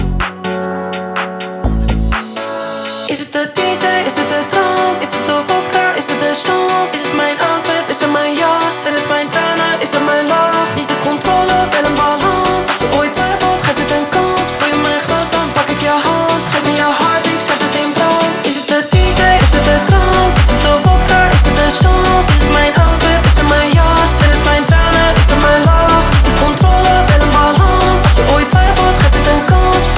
Pop
Жанр: Поп музыка